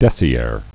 (dĕsē-âr, -är)